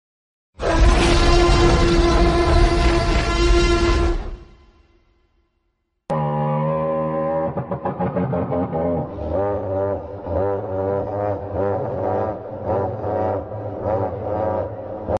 The Real Sound Of T Rex Sound Effects Free Download